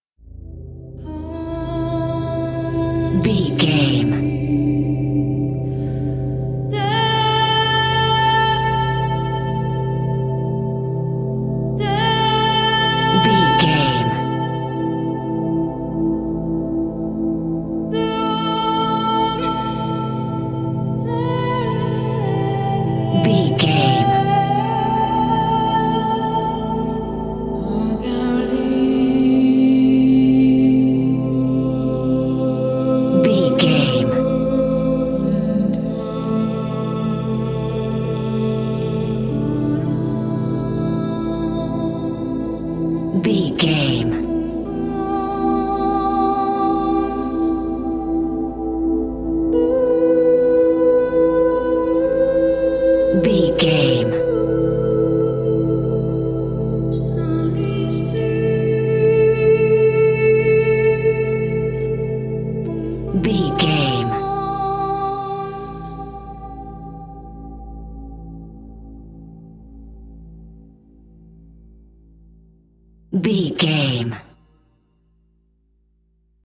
Thriller
Aeolian/Minor
vocals
synthesiser